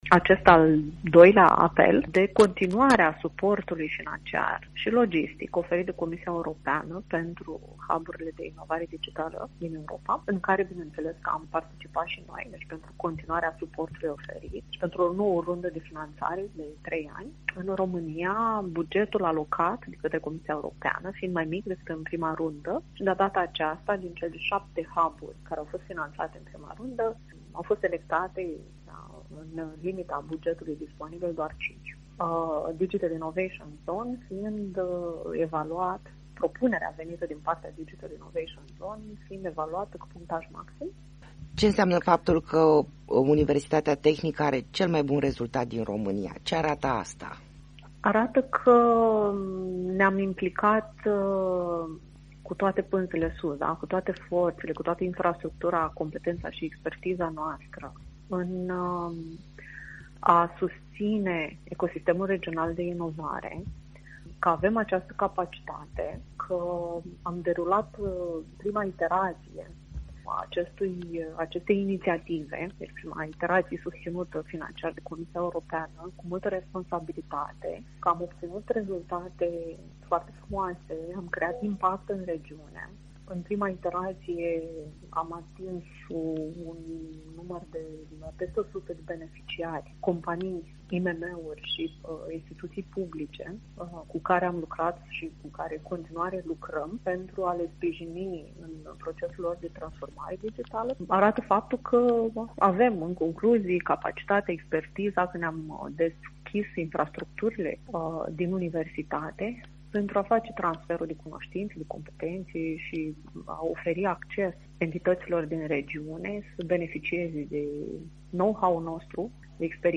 Interviu-TUIASI-august.mp3